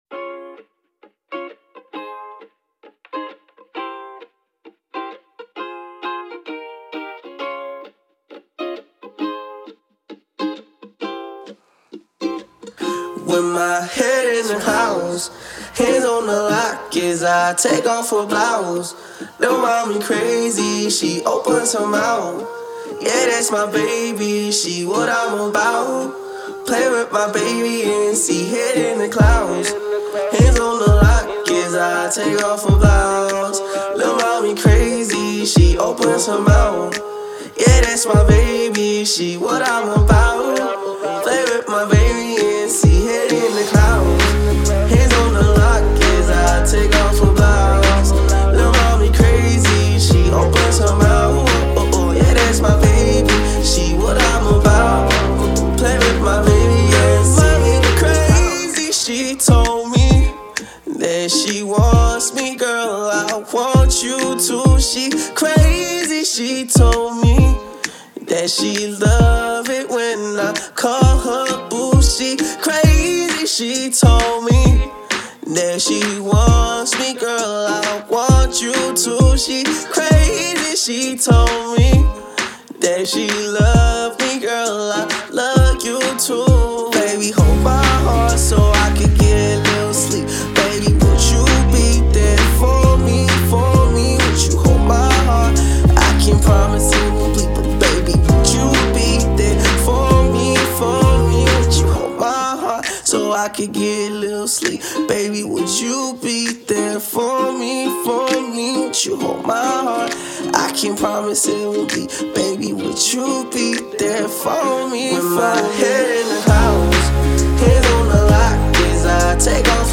Pop
the Atlanta-based artist fusing Alternative Pop and Hip-Hop.